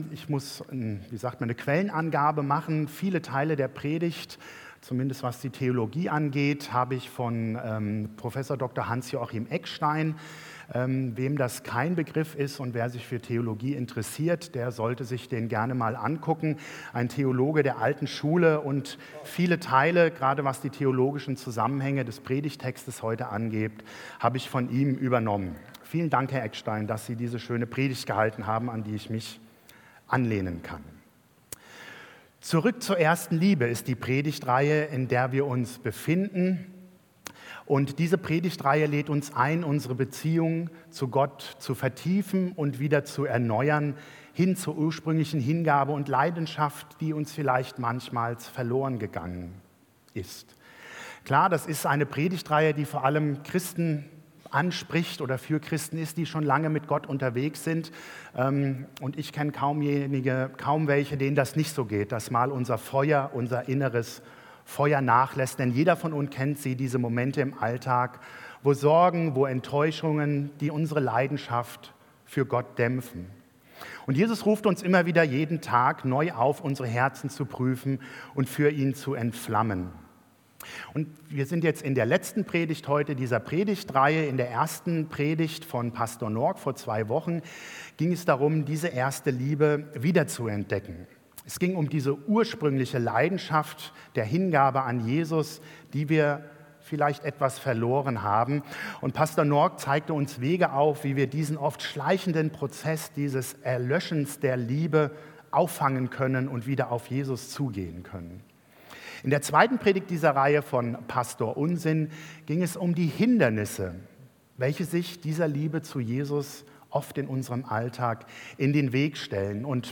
Predigt Zurück zur ersten Liebe